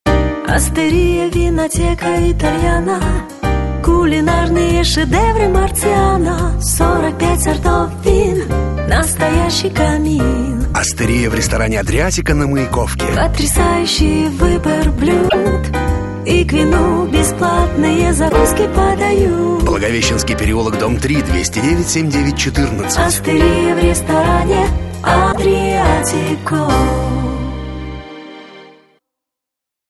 рекламный ролик